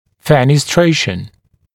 [ˌfenɪˈstreɪʃ(ə)n][ˌфэниˈстрэйш(э)н]фенестрация (создание отверстия), порозность; ячеистая структура; сообщение; свищ